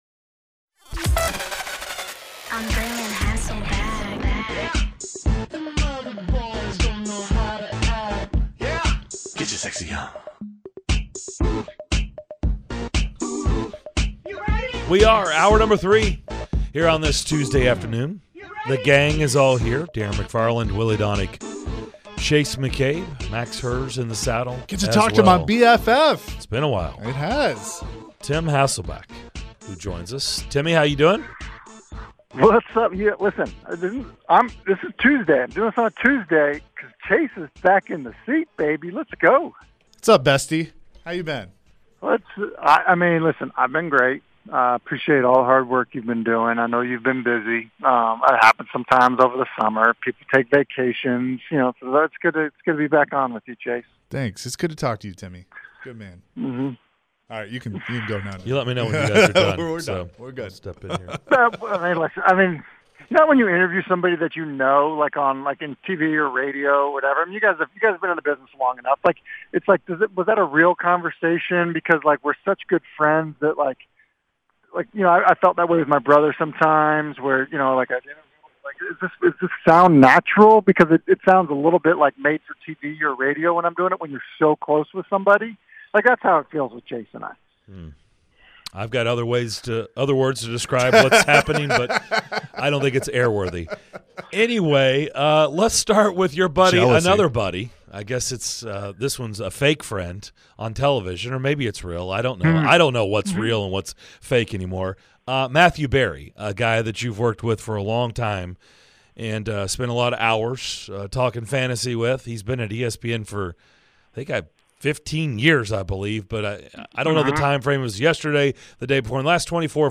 Tim Hasselbeck Full Interview (07-12-22)